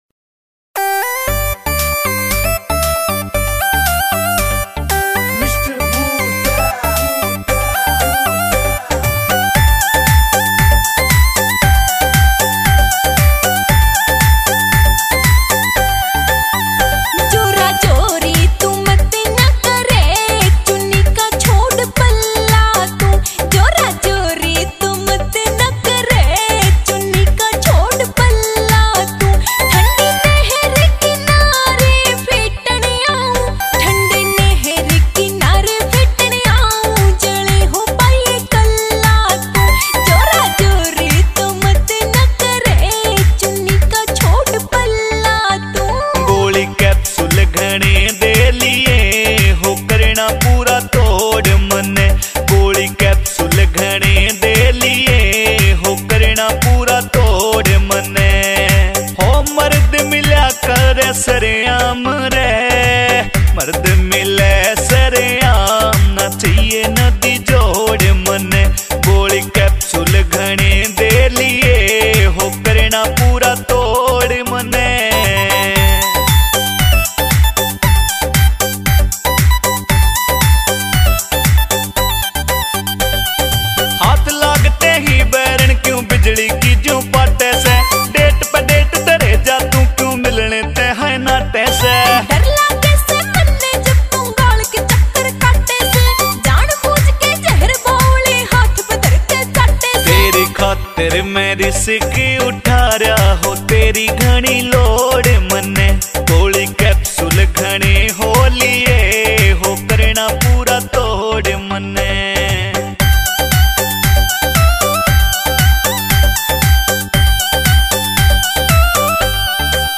» Haryanvi Songs